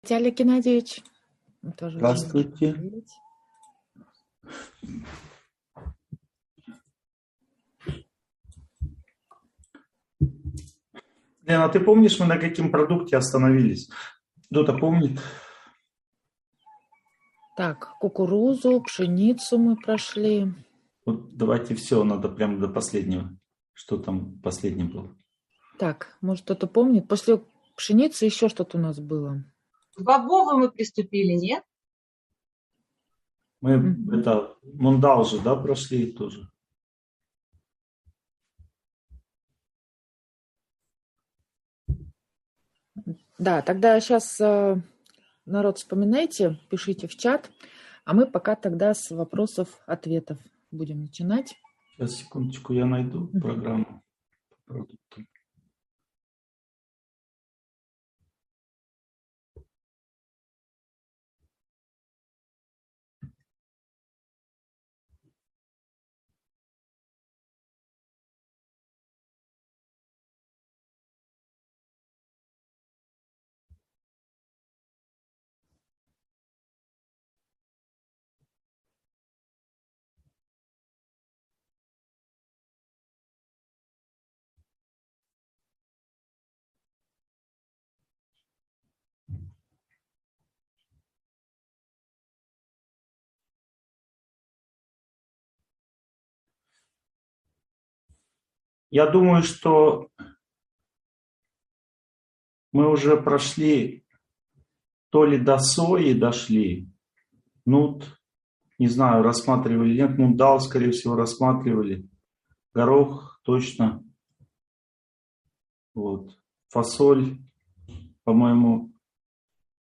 Питание. Углубленное изучение темы. Часть 3 (онлайн-семинар, 2022)